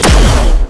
fire_no_torpedo.wav